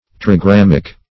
trigrammic - definition of trigrammic - synonyms, pronunciation, spelling from Free Dictionary Search Result for " trigrammic" : The Collaborative International Dictionary of English v.0.48: Trigrammic \Tri*gram"mic\, a. [Gr.